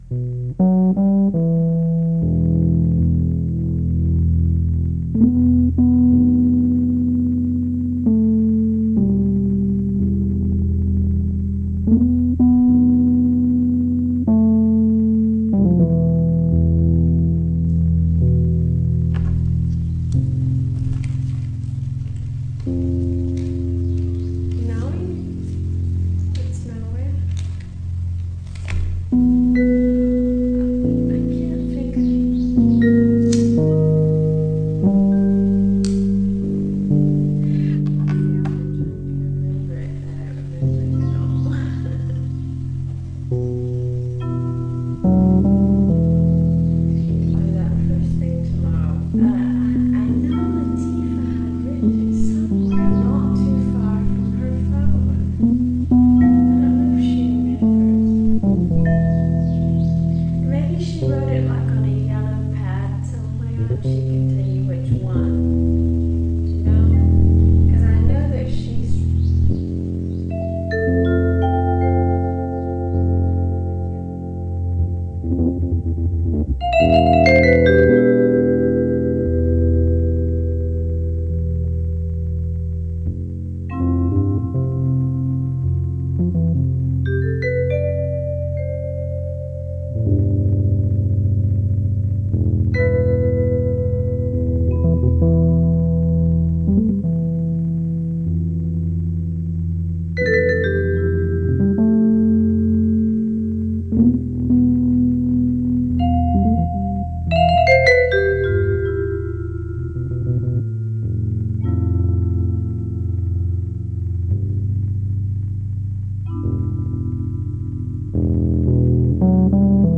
Lounge Music